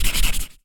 Sfx Crayon Sound Effect
sfx-crayon-5.mp3